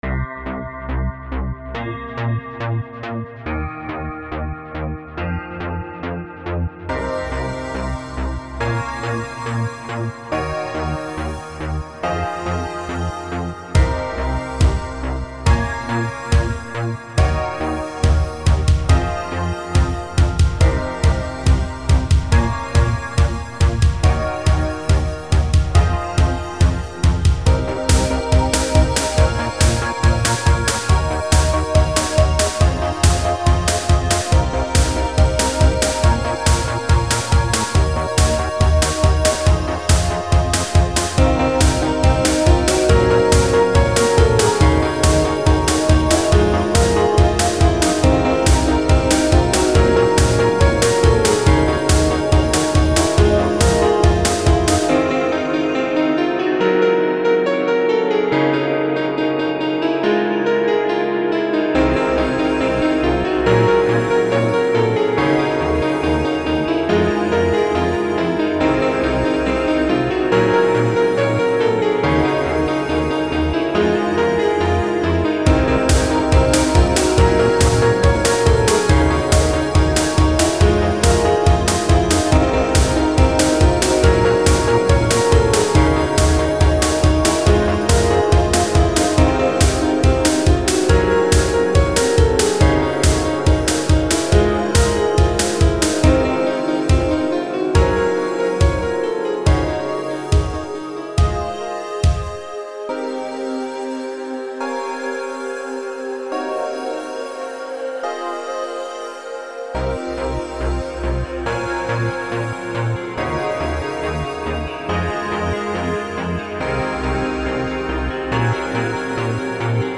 Sounds nice,but a bit wierd.
Anyway kickass sound Very Happy